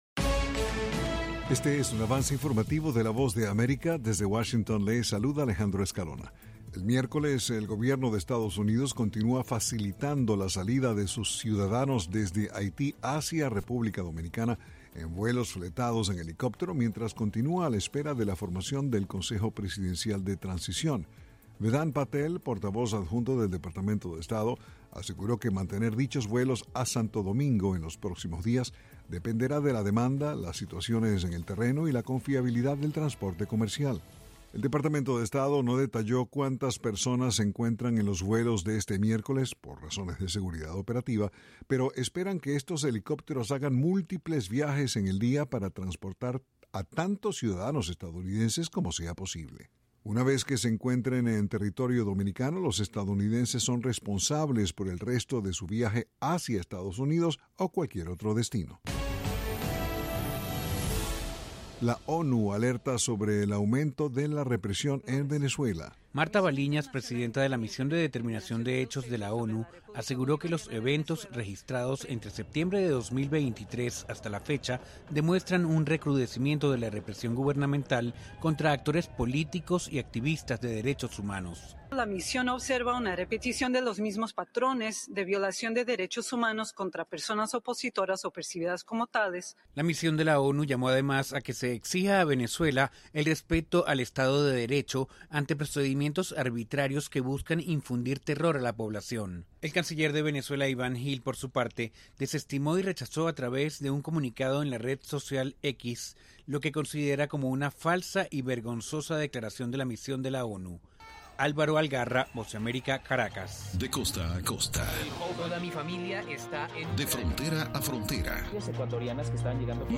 Este es un avance informativo presentado por la VOA en Washington.